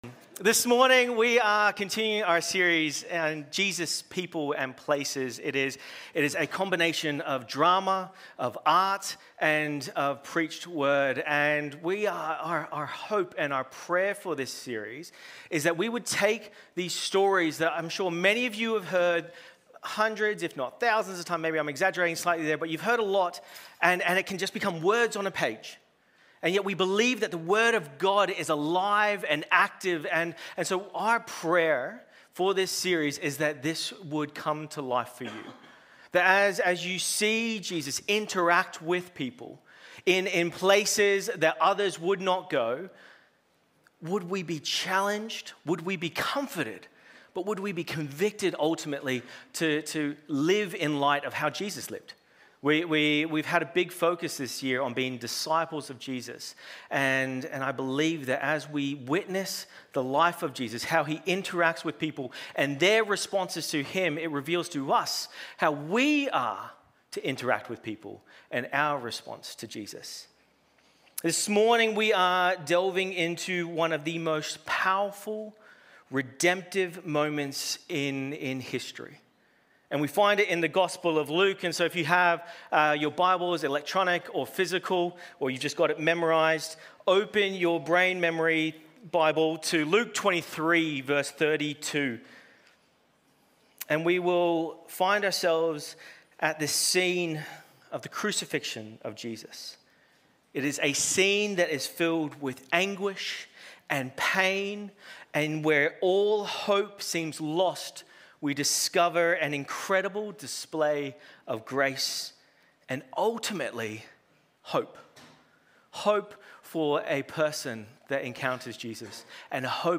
Sermons | Titirangi Baptist Church
As we bring these stories to life through the synergy of preaching and dramatic performance, we pray that it stirs a desire in all of us to extend the life-changing gospel of Jesus to those around us, fostering a community rooted in compassion and action. Today we are looking at Luke 23:32-43 where Jesus encounters the "worst person" on the worst day but brings hope and reveals the model of